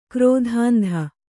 ♪ krōdhāndha